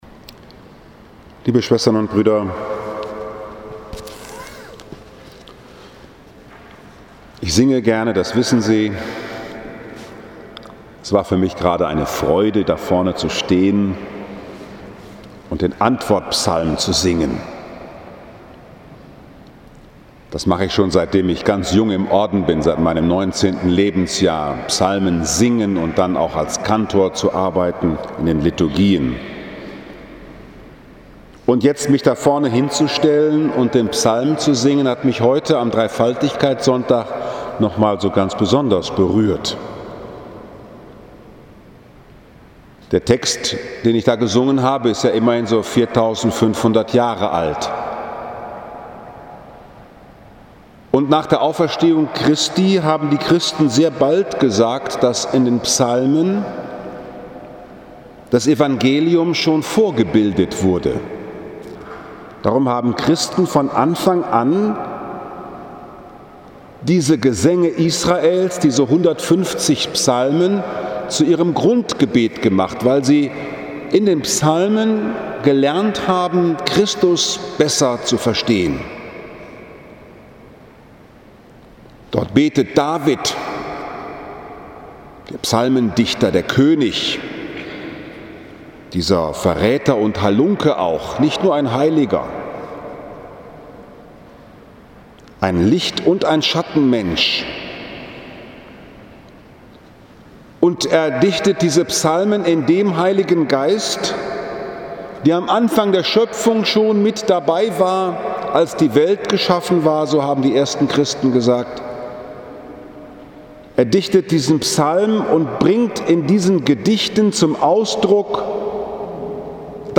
Frankfurter Dom, Dreifaligkeitssonntag
Kapuzinerpredigt Podcast